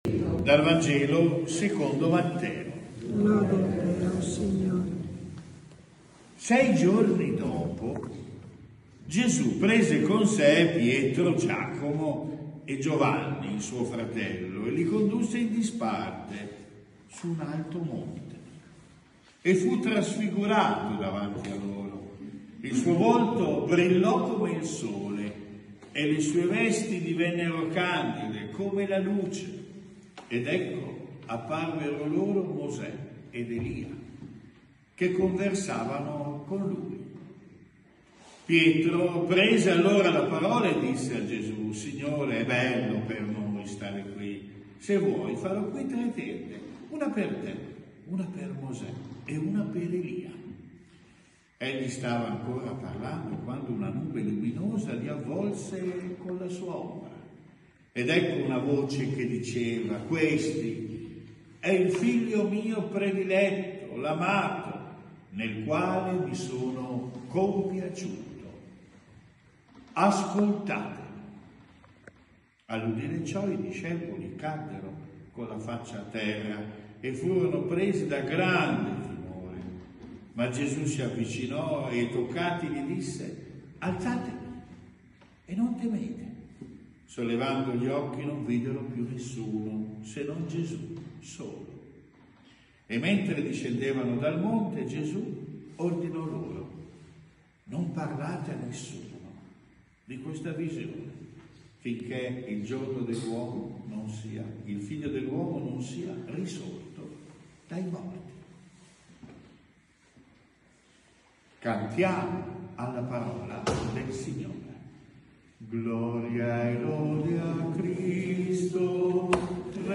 Omelia II quar. Anno A – Parrocchia San Pellegrino
Segnaliamo che a causa di un leggero problema durante la registrazione, la riproduzione dal minuto 9:29 al minuto 9:34 risulta inudibile, pur senza che si perda il filo logico del discors.